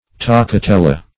Search Result for " toccatella" : The Collaborative International Dictionary of English v.0.48: Toccatella \Toc`ca*tel"la\, Toccatina \Toc`ca*ti"na\, n.]